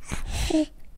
byawn1.ogg